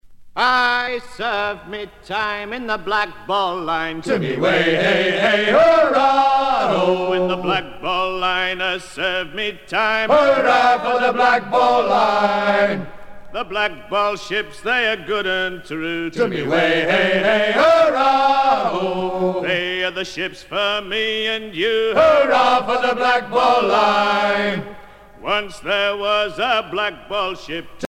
à virer au guindeau
Pièce musicale éditée